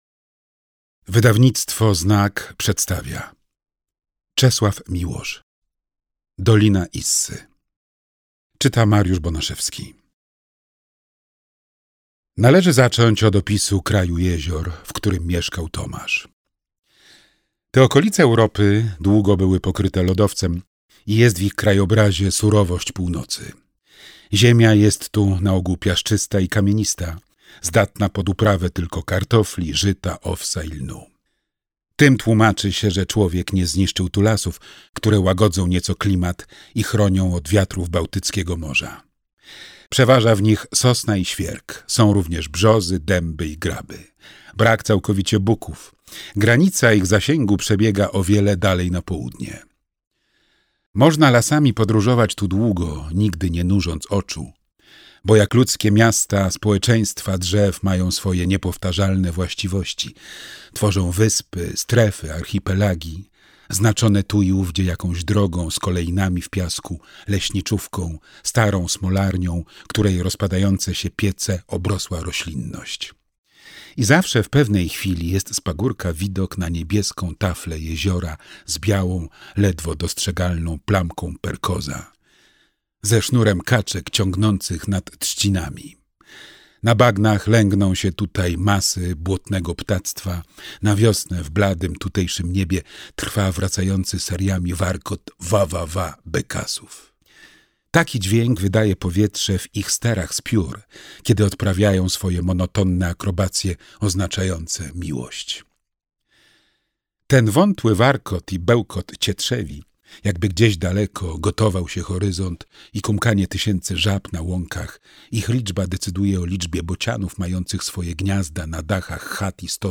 Dolina Issy - Czesław Miłosz - audiobook